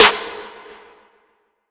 DDK1 SNARE 4.wav